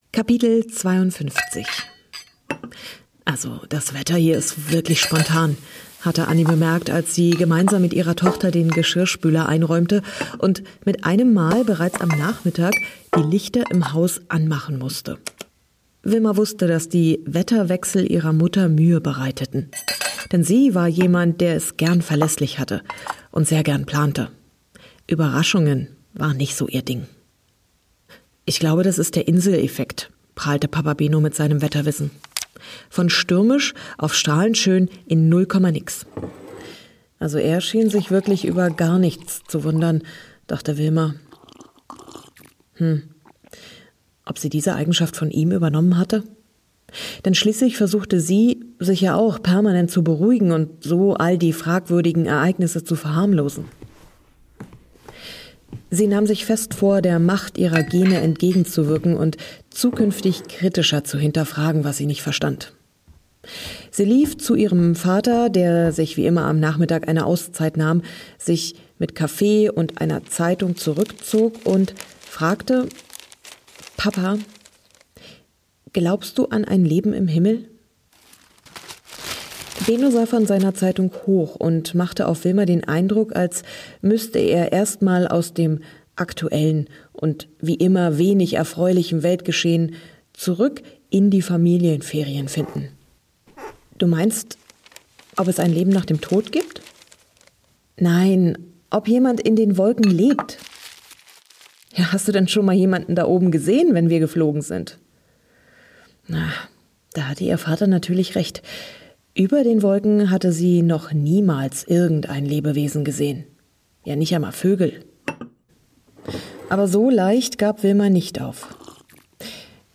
Ein atmosphärisches Hörerlebnis für alle, die sich gern davon und in die Wolken träumen.